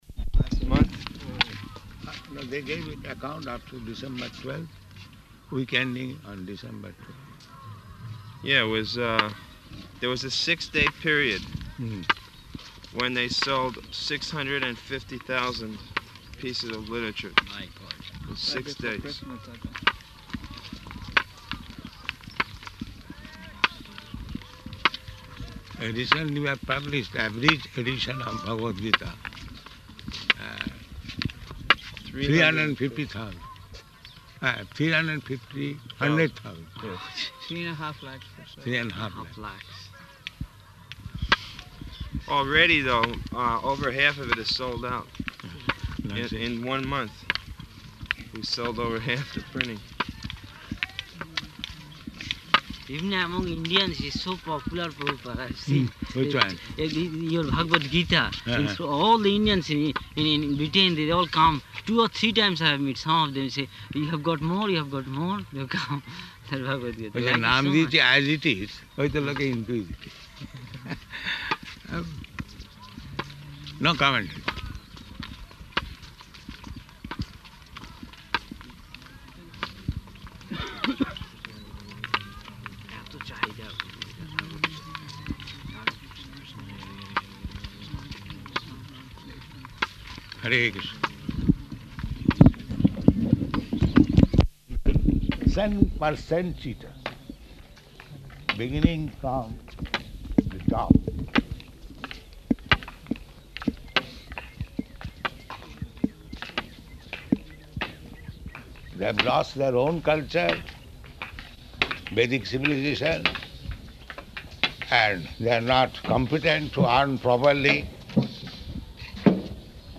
-- Type: Walk Dated: January 23rd 1976 Location: Māyāpur Audio file